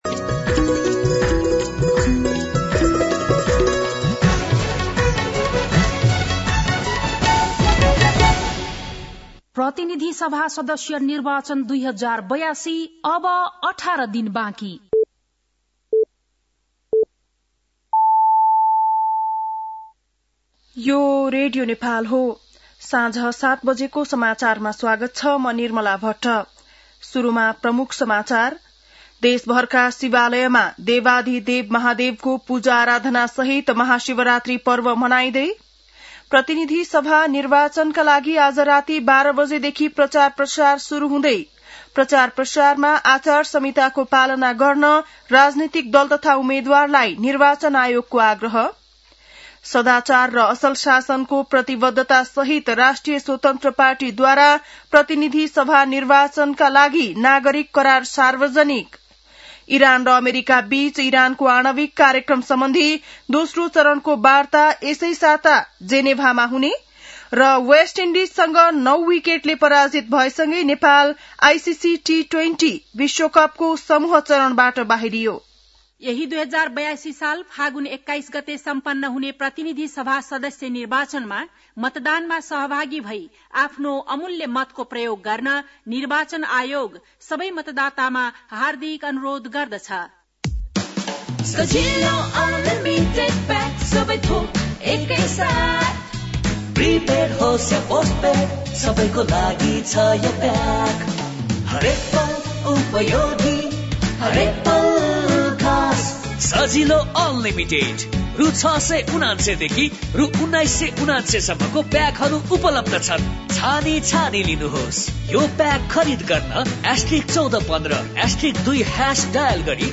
बेलुकी ७ बजेको नेपाली समाचार : ३ फागुन , २०८२